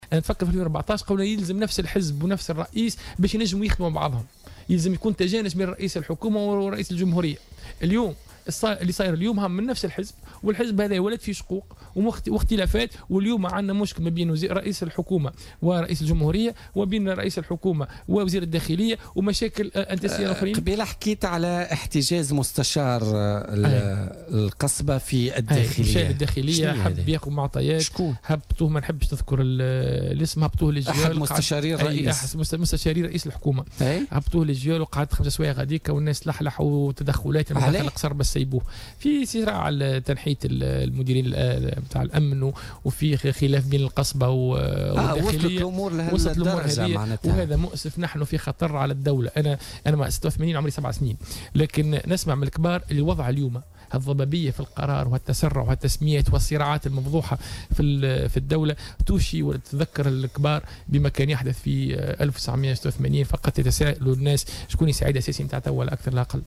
وأوضح العياري، في حوار مع الجوهرة أف أم، خلال برنامج بوليتكا، اليوم الأربعاء، أن الحادثة تندرج في إطار "الصراعات" القائمة بين وزارة الداخلية ورئاسة الحكومة وتبعات إعفاء مدير عام الأمن الوطني، واصفا الوضع بالخطير والذي يعيد إلى الأذهان سيناريو سنة 1986.